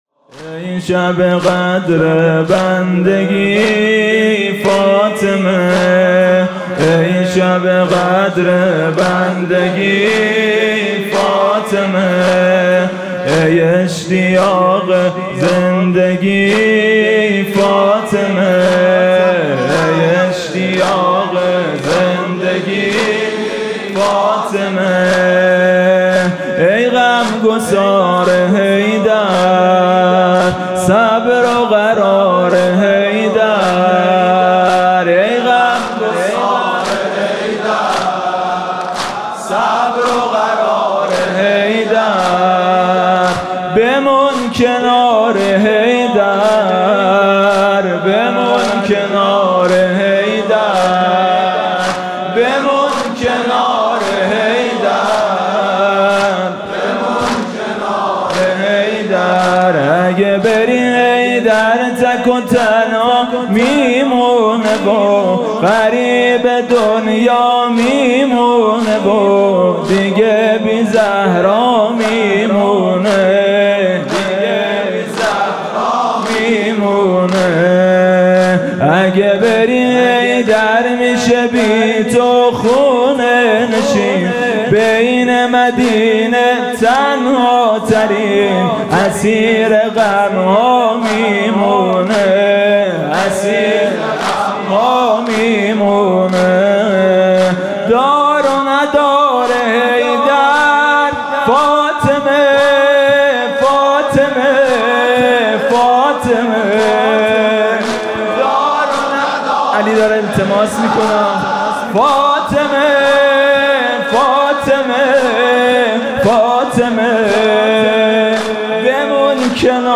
مناسبت: قرائت دعای ۱۴ صحیفه سجادیه و عزاداری ایام شهادت حضرت زهرا (س)
با نوای: حاج میثم مطیعی